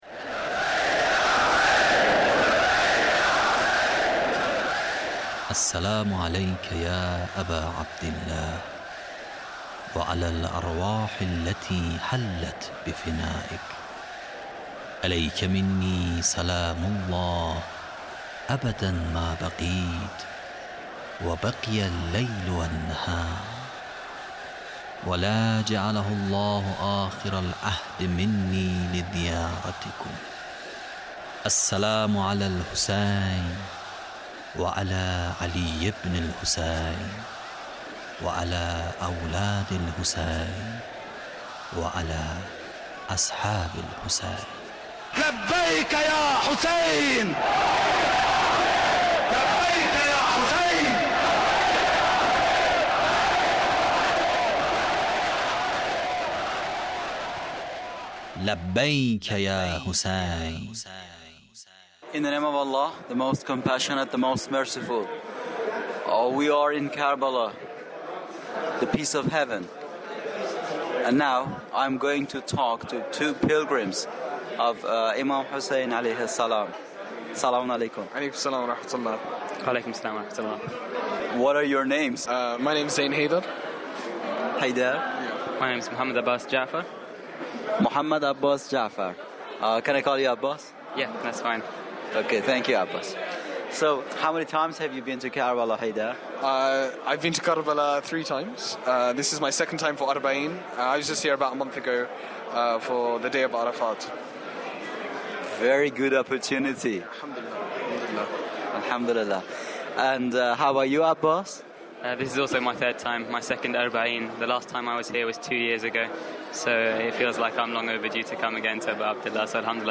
Interview with pilgrim of imam hussain (PART1)